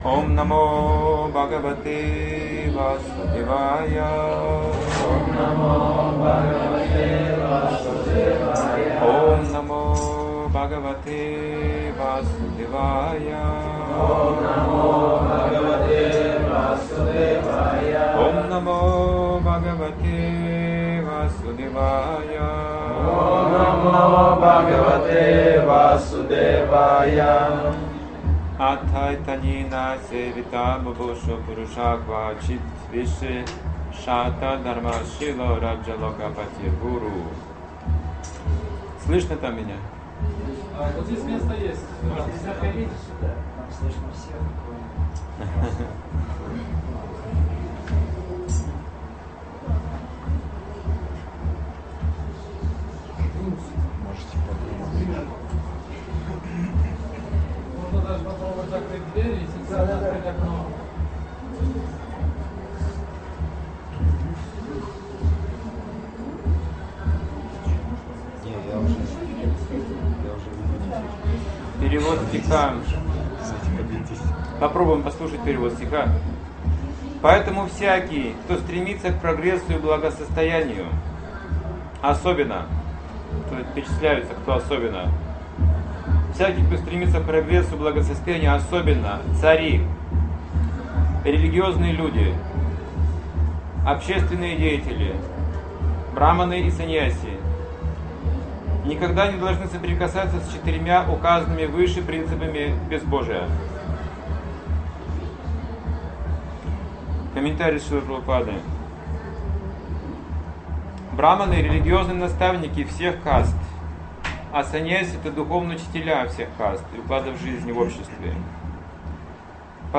Удупи